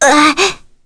Leo-Vox_Damage_kr_01.wav